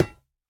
Minecraft Version Minecraft Version latest Latest Release | Latest Snapshot latest / assets / minecraft / sounds / block / netherite / break3.ogg Compare With Compare With Latest Release | Latest Snapshot
break3.ogg